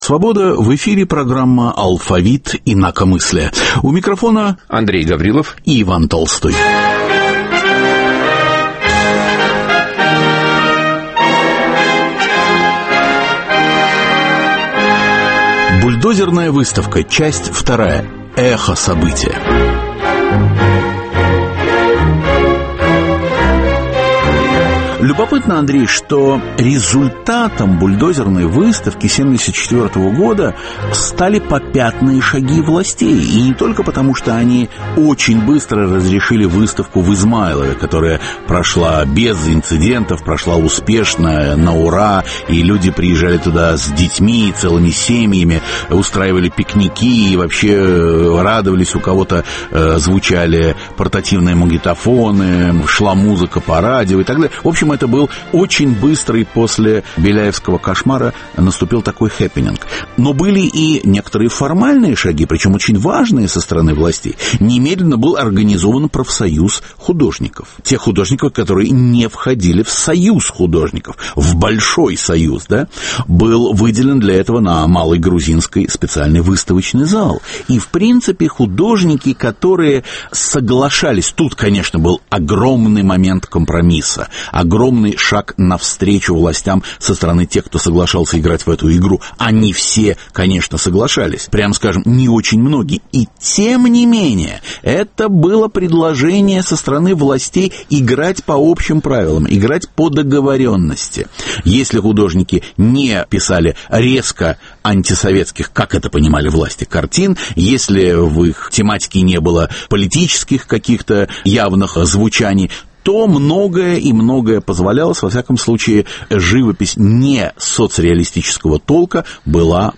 В программе - участники Бульдозерной выставки, свидетели и современники.